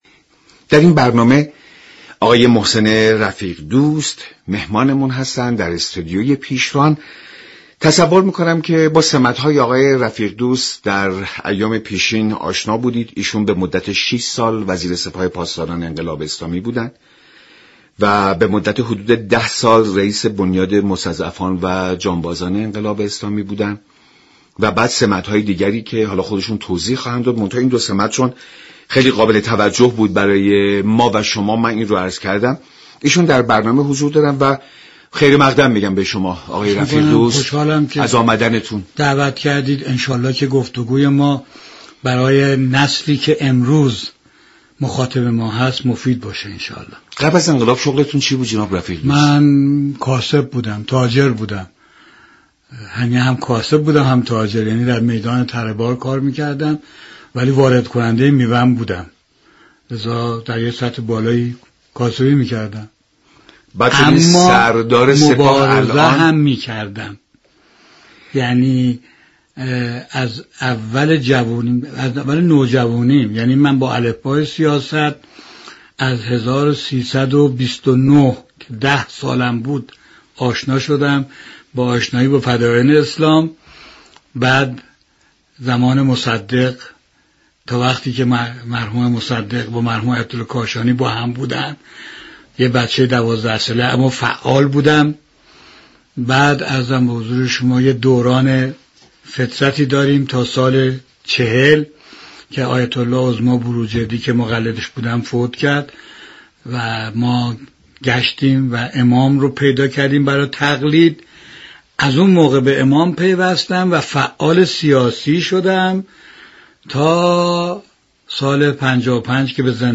محسن رفیق دوست در گفت و گو با رادیو ایران گفت